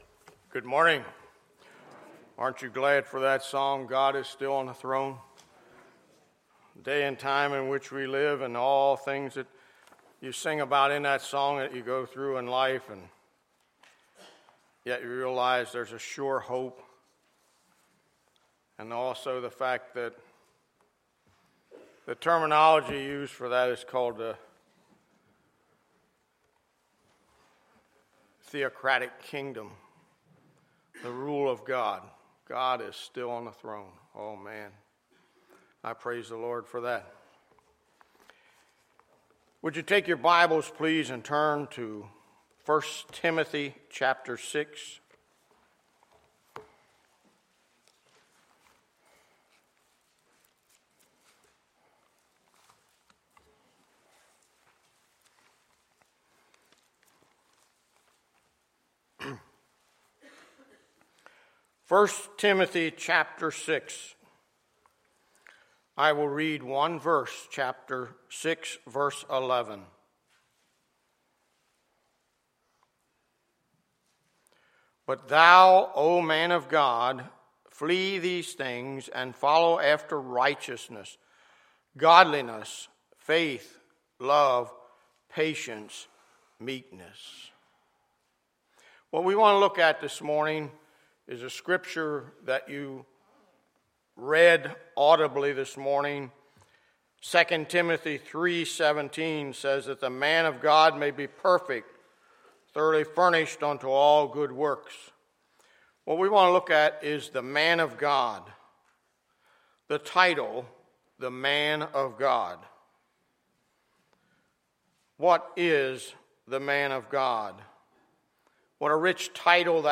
Sunday, April 17, 2016 – Sunday Morning Service